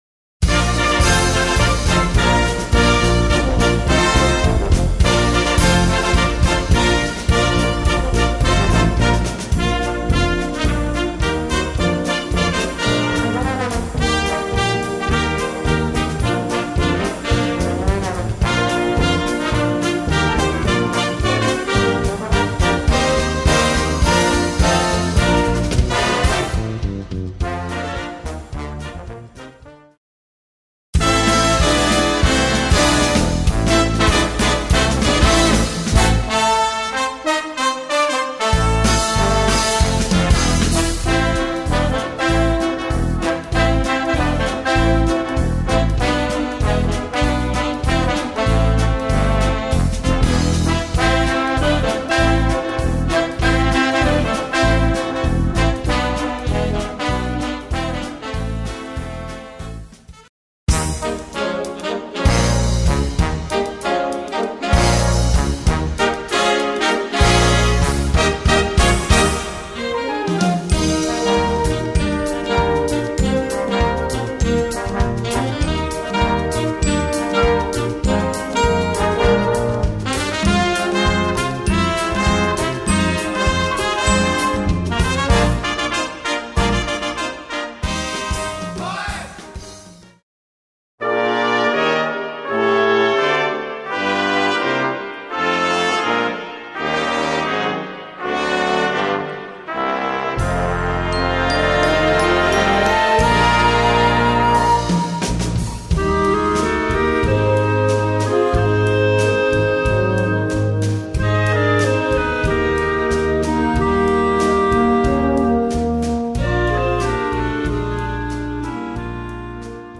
Concert Band (March Card Size)